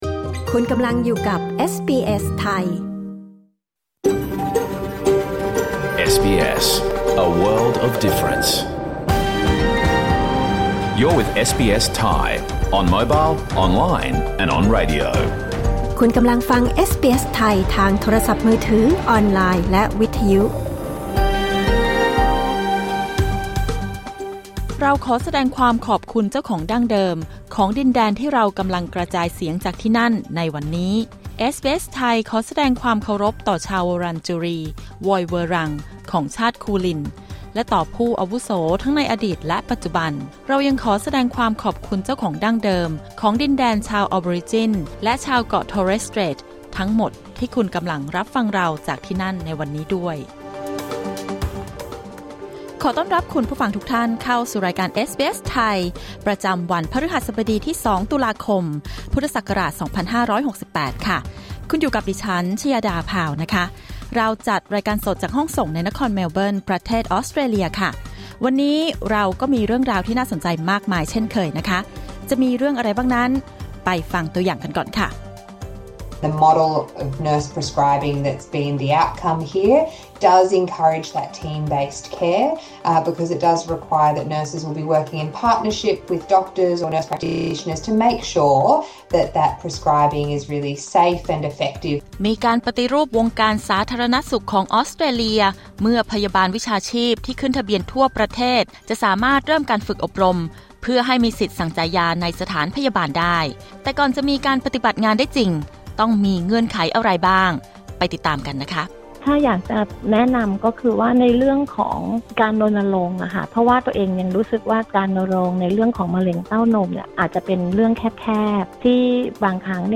รายการสด 2 ตุลาคม 2568